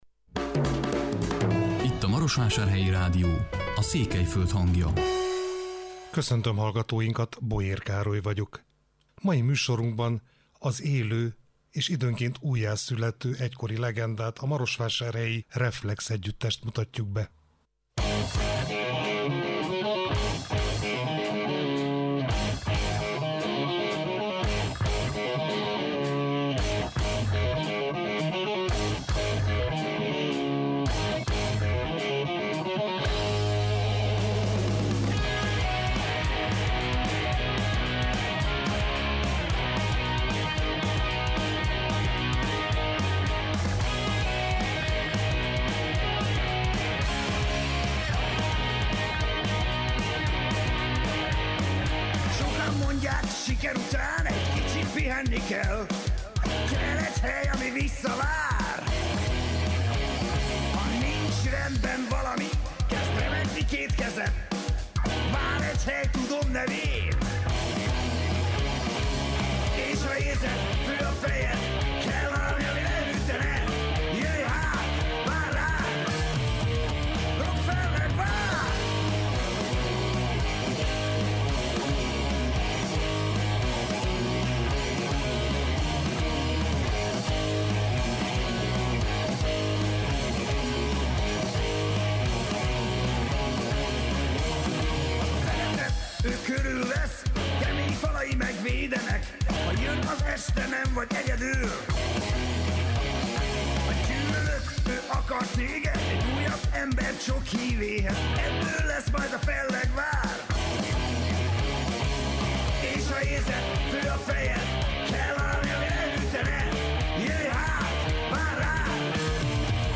melyben a Reflex három alapítótagja szólal meg, az együttes első, egyben aranylemezÉNEK megjelenése alkalmából.